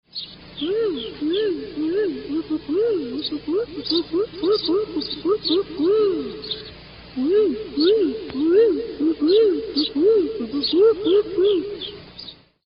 admiralbirdscall.mp3